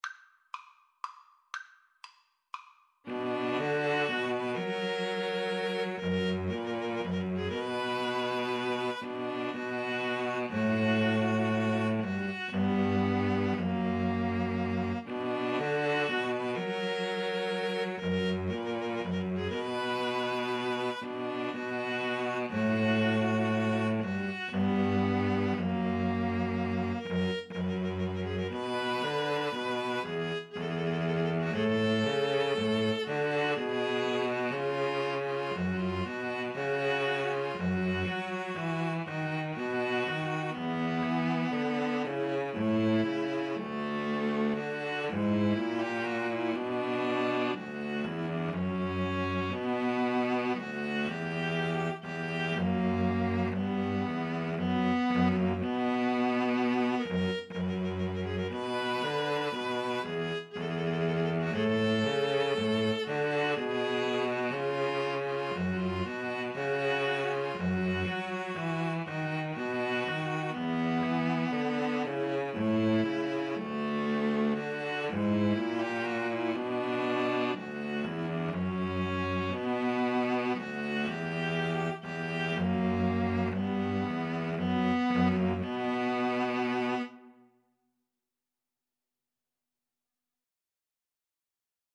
Vivace (View more music marked Vivace)
String trio  (View more Intermediate String trio Music)
Classical (View more Classical String trio Music)